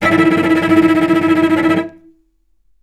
Strings / cello / tremolo
vc_trm-E4-mf.aif